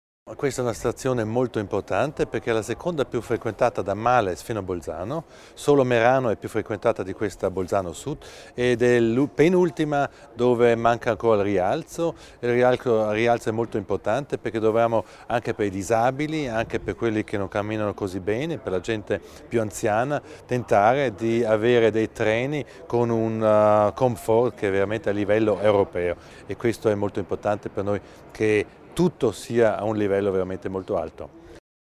L'Assessore Widmann spiega i principali interventi alla stazione ferroviaria di Bolzano Sud